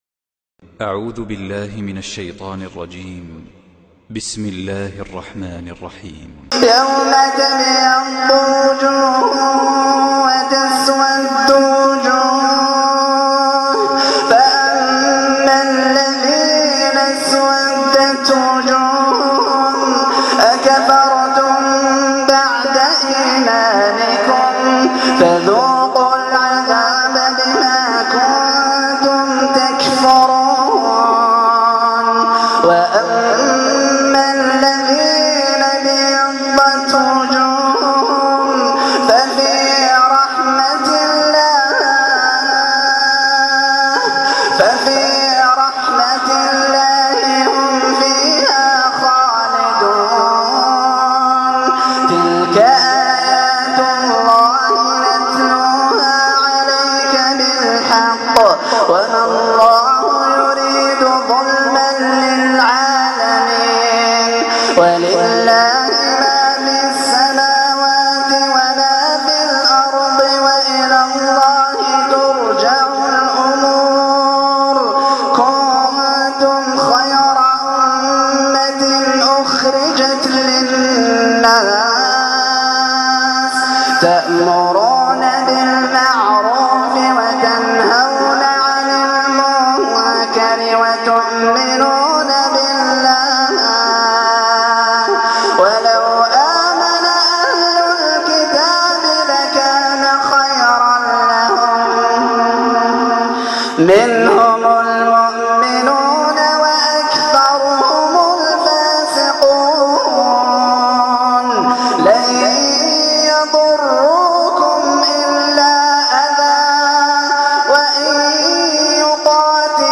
تحميل تلاوة القائ